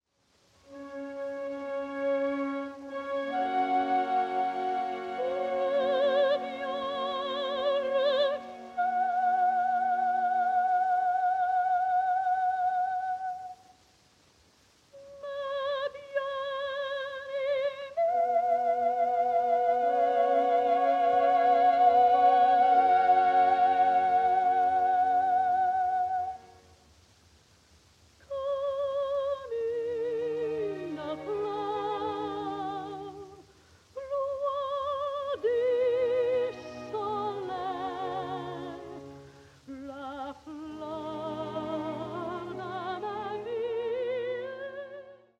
その歌声は可憐。
SP盤のノイズと共にまろやかさが増す歌声。
録音：1932〜48年　モノラル録音